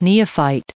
added pronounciation and merriam webster audio
996_neophyte.ogg